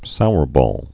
(sourbôl)